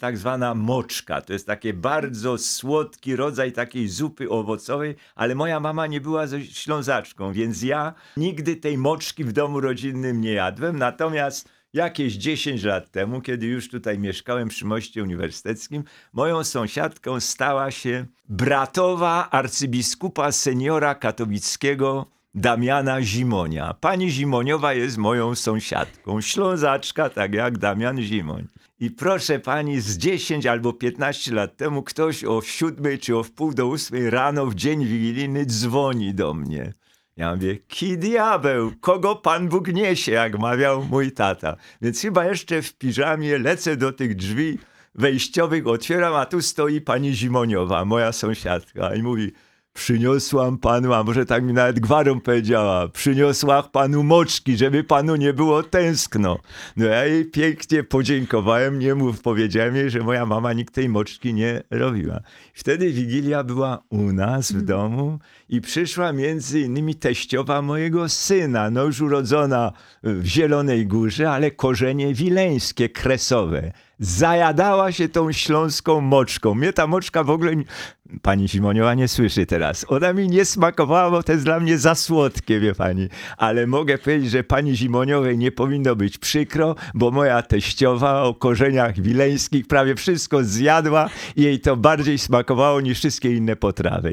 Językoznawca, prof. Jan Miodek odwiedził w pierwszy dzień świąt studio Radia Rodzina.